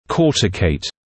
[‘kɔːtɪkeɪt][‘коːтикэйт]покрываться кортикальным слоем